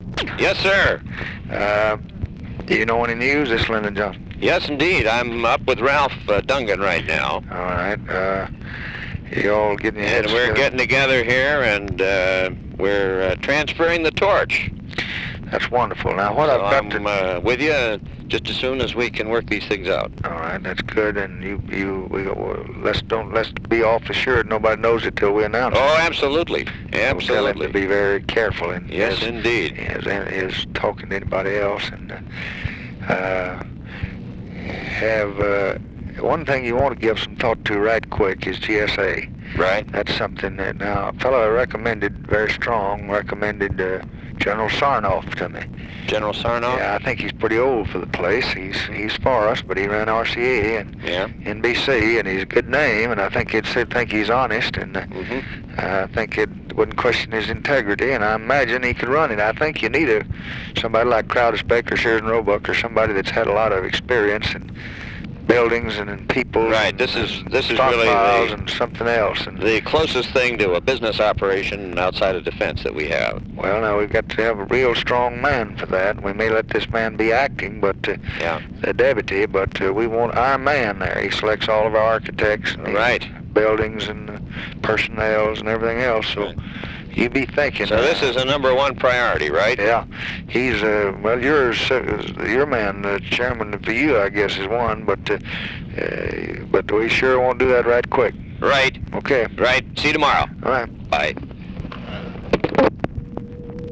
Conversation with JOHN MACY, November 16, 1964
Secret White House Tapes